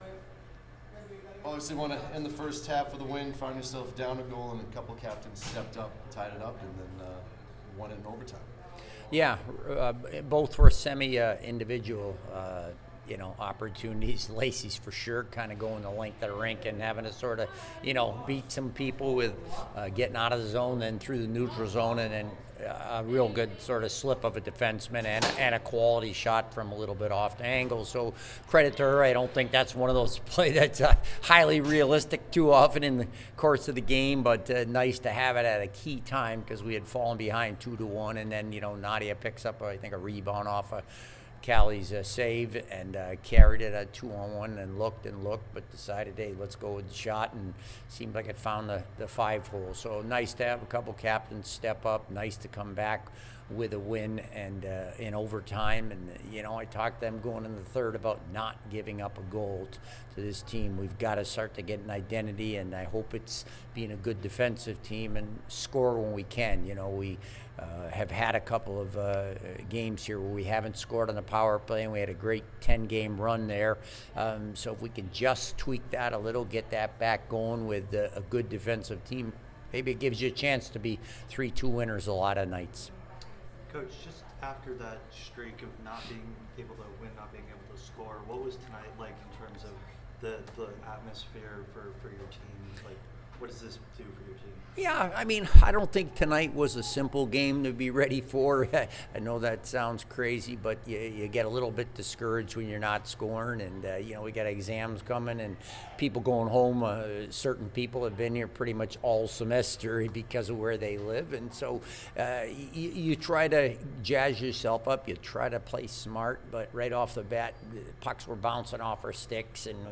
Women's Ice Hockey / Holy Cross Postgame Interview (12-9-22)